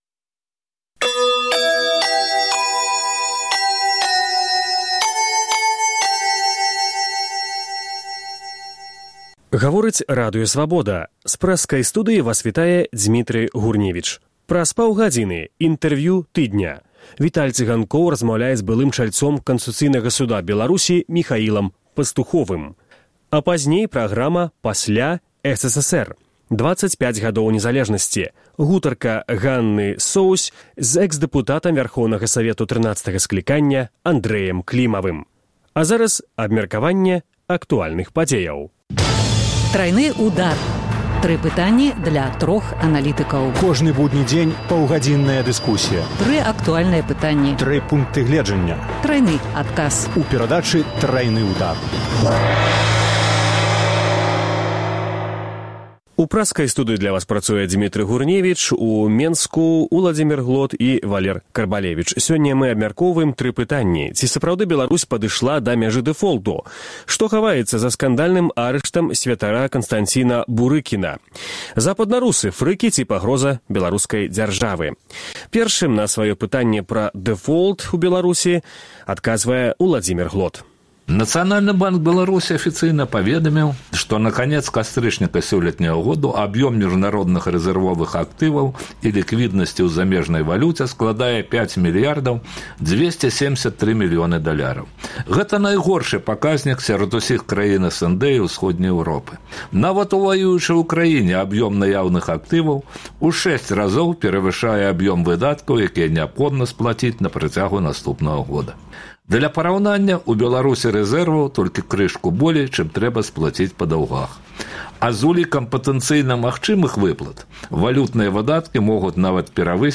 Тры пытаньні для трох аналітыкаў.